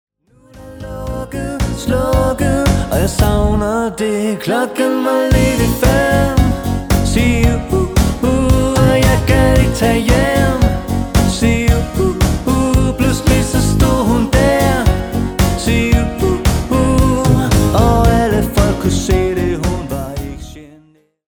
Dansemusik for alle aldre.
• Coverband